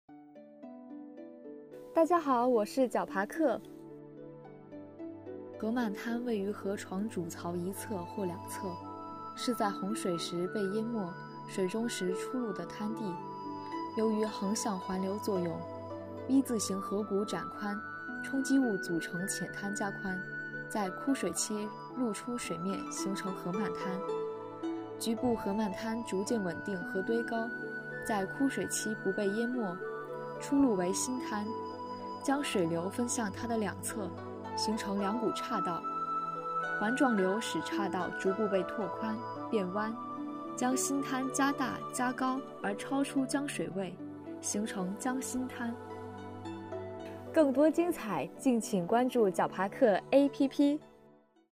河漫滩*江心滩----- 手机用户 解说词: 河漫滩位于河床主槽一侧或两侧，在洪水时被淹没时，水中出露的滩地，由于横向环流作用，V字形河谷展宽，冲积物组成浅滩加宽，在枯水期露出水面形成河漫滩。